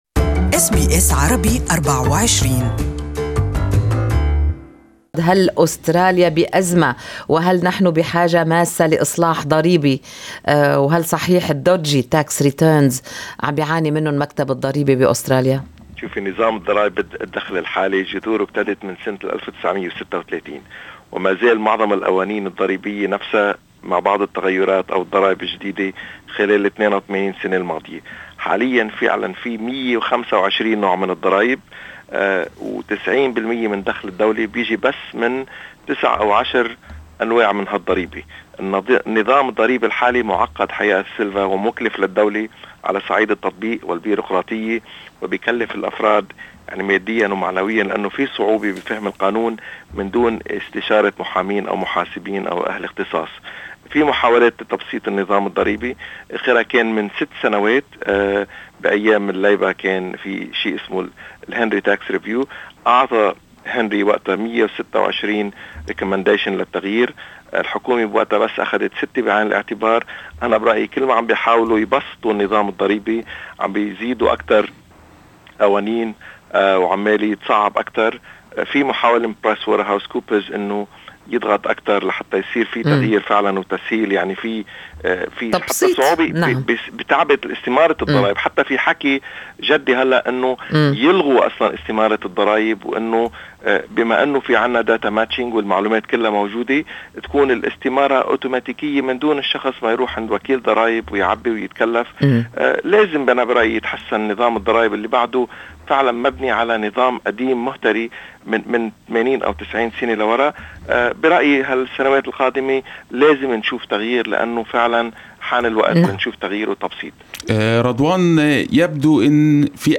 Listen to the full interview in Arabic above.